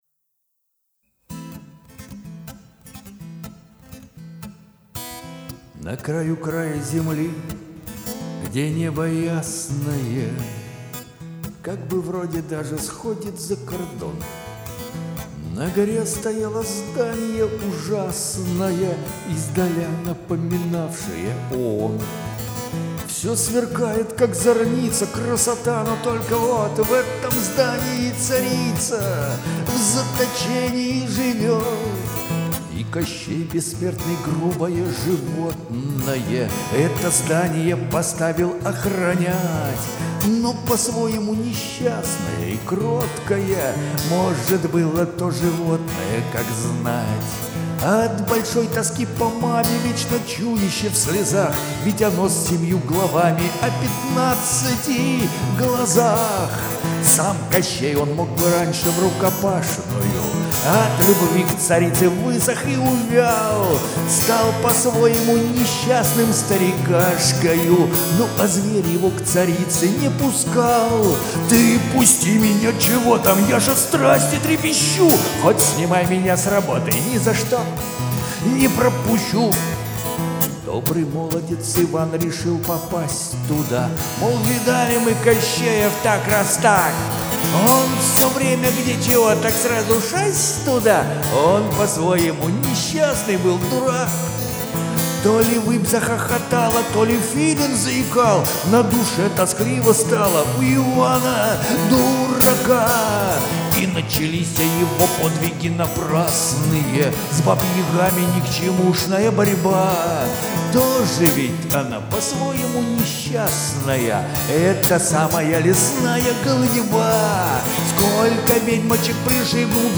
в моём исполнении